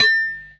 Añadido el sonido de coger objetos
item.wav